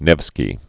(nĕvskē, nĕf-) 1220?-1263.